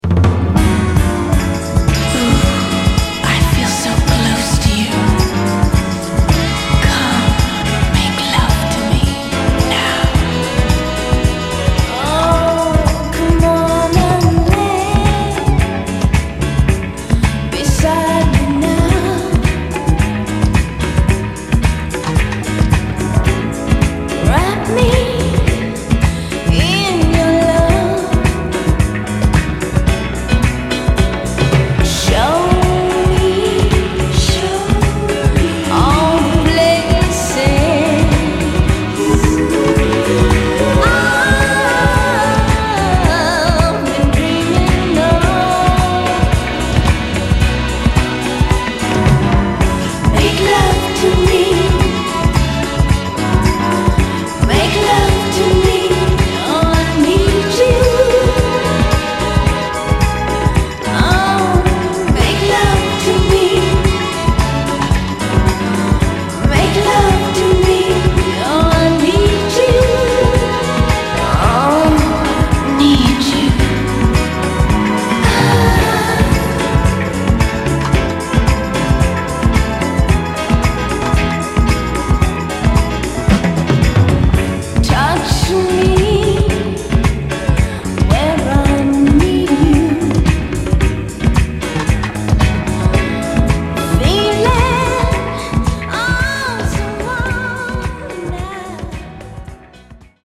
、ドリーミーでクロスオーヴァーなメロウ・ソウル・ダンサーで最高です！
※試聴音源は実際にお送りする商品から録音したものです※